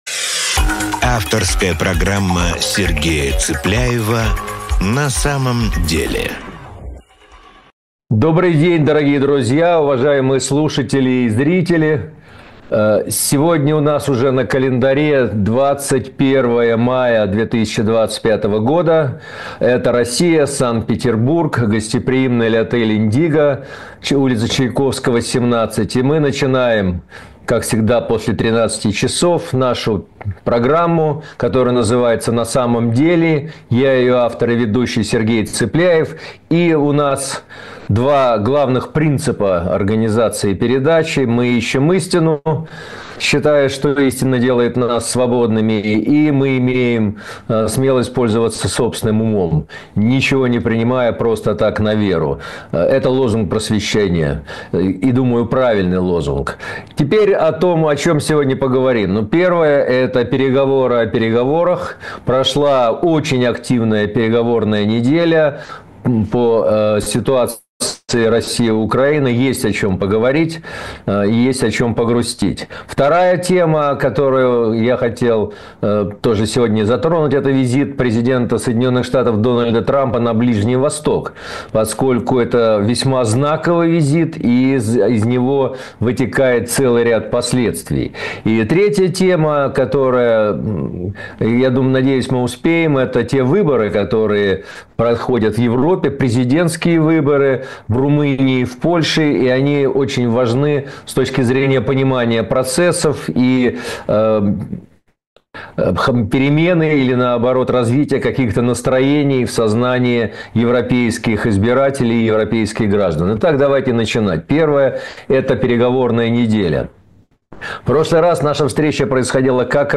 Программу ведет Сергей Цыпляев